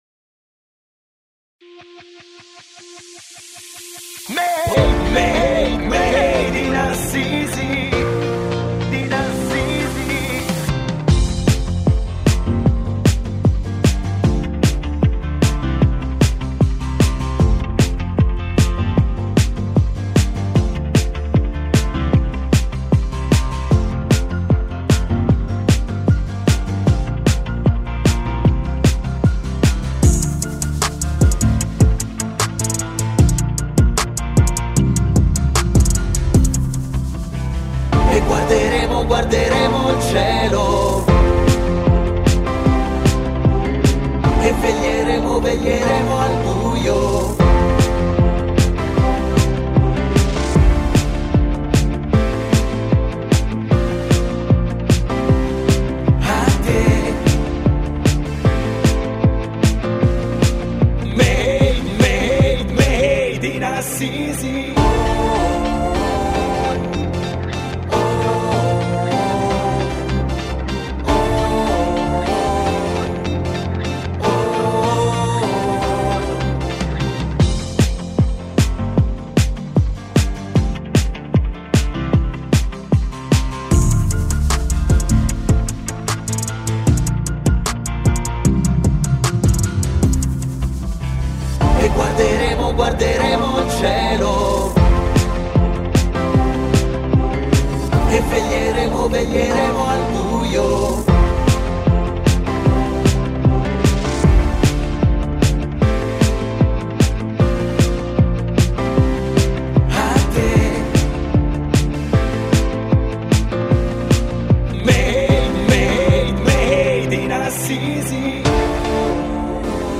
MADE-IN-ASSISI-BASE-CORI.mp3